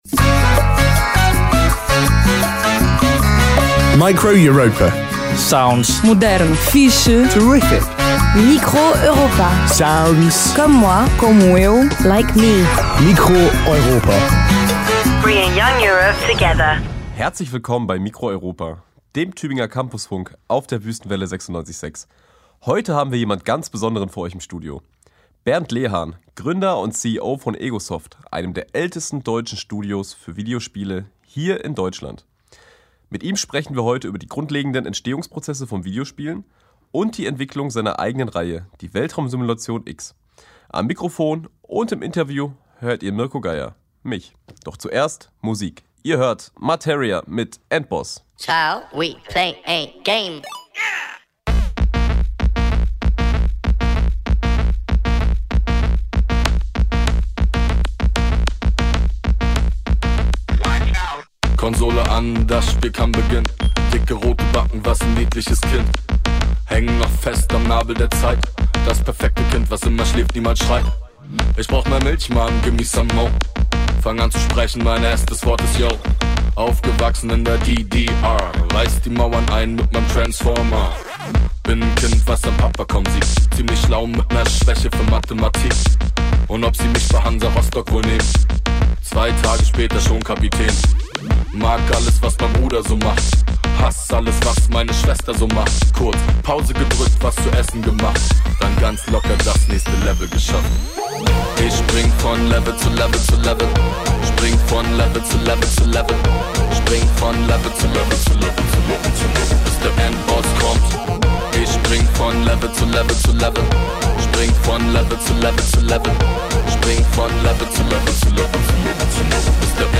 Videospiele: Studiogespräch
Form: Live-Aufzeichnung, geschnitten